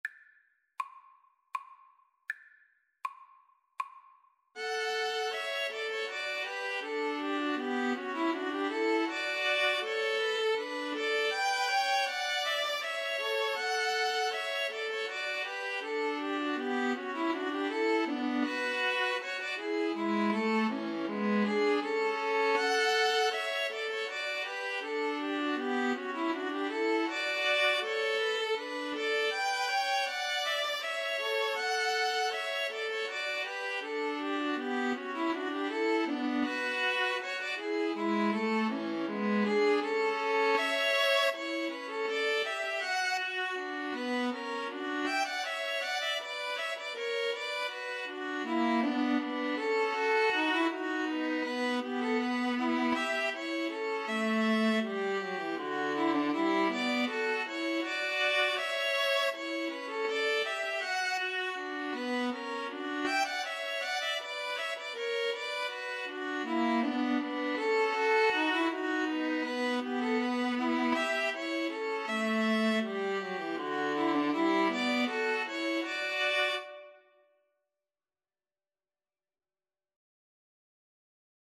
Classical Handel, George Frideric Hornpipe from Water Music String trio version
ViolinViolaCello
Allegro = c.80 (View more music marked Allegro)
D major (Sounding Pitch) (View more D major Music for String trio )
3/2 (View more 3/2 Music)
Classical (View more Classical String trio Music)